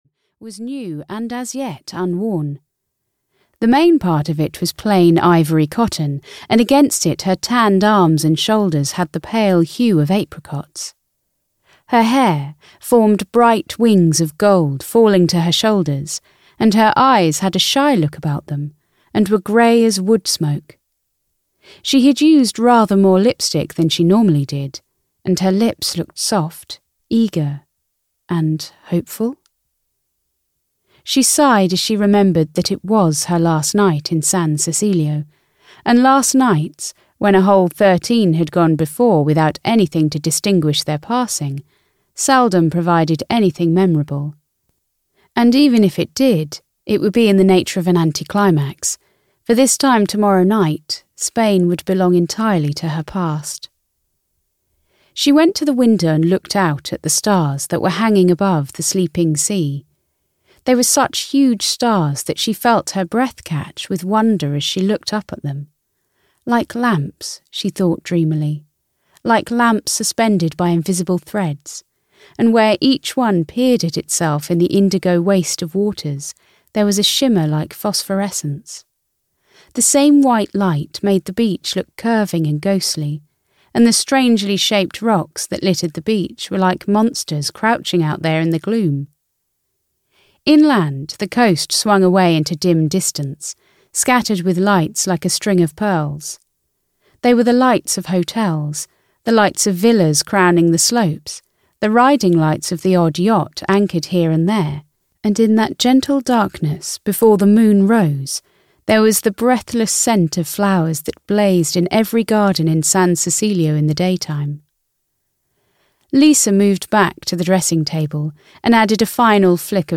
The Stars of San Cecilio (EN) audiokniha
Ukázka z knihy